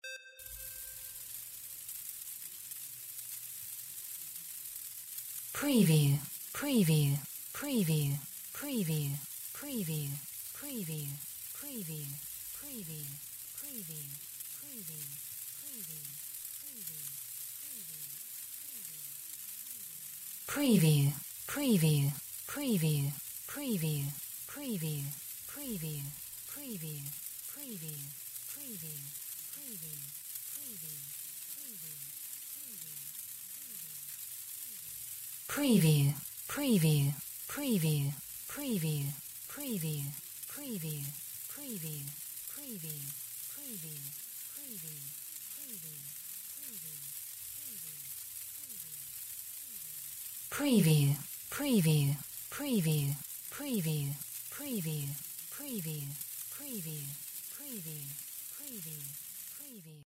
Stereo sound effect - Wav.16 bit/44.1 KHz and Mp3 128 Kbps
previewWEA_RAIN_ICE_WBHD01.mp3